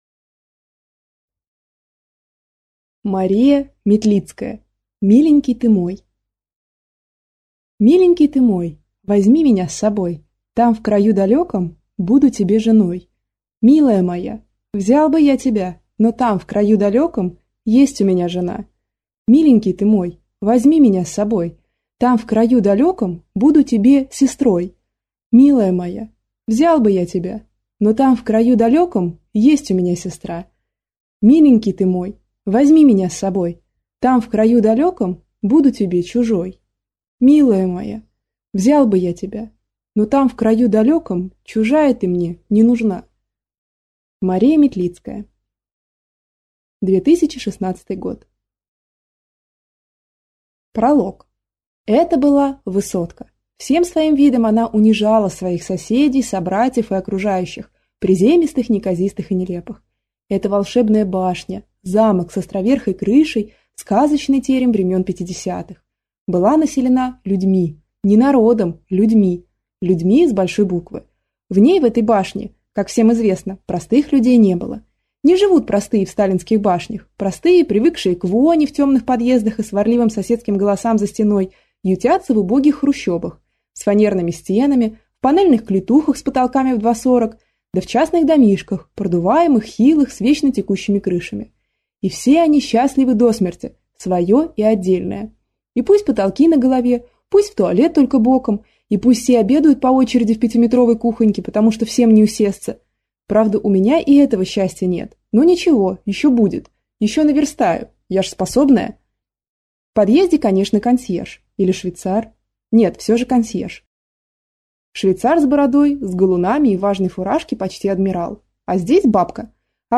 Аудиокнига Миленький ты мой | Библиотека аудиокниг